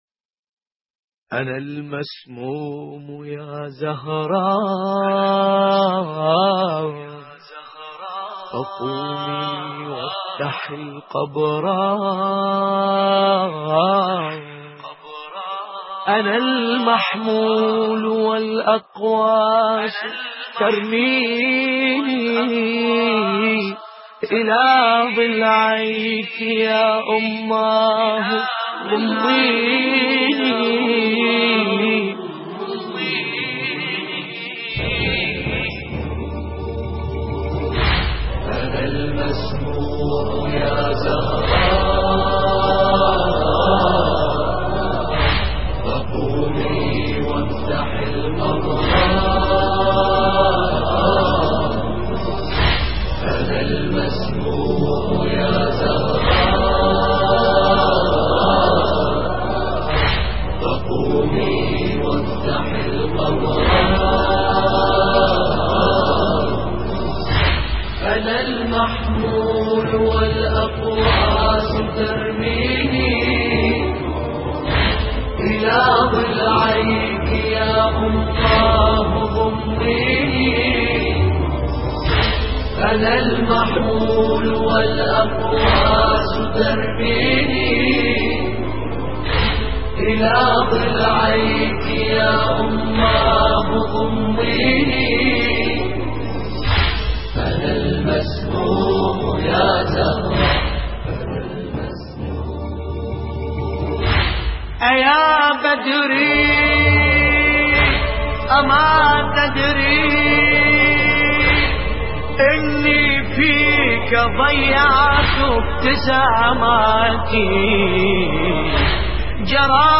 دانلود مداحی عن المسموم یا زهرا - دانلود ریمیکس و آهنگ جدید
مرثیه خوانی ملا باسم کربلایی به مناسبت شهادت حضرت زهرا(س) (12:28)